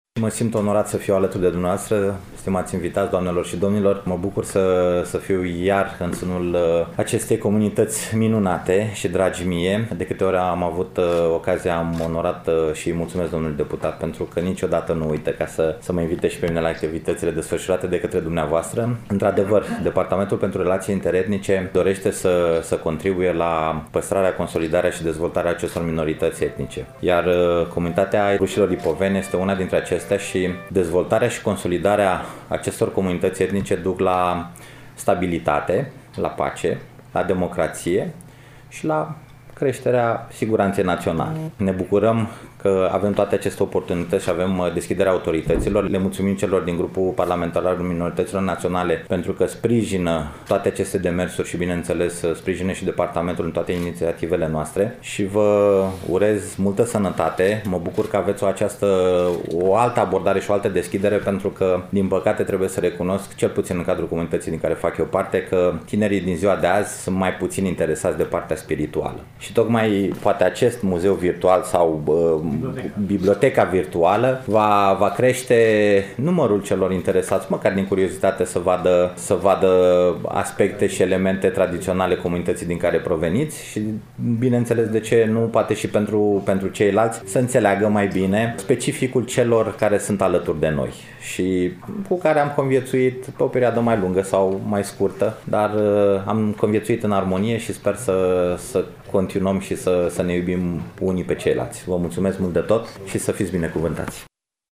Evenimentul a avut loc, nu demult, în Amfiteatrul „I. H. Rădulescu” al Bibliotecii Academiei Române, București.
Așadar, în acest context, în deschiderea ediției de astăzi, difuzăm, de la prezentarea catalogului amintit, discursul domnului Dincer Geafer, secretar de stat în cadrul Secretariatului General al Guvernului României, Departamentul pentru Relații Interetnice.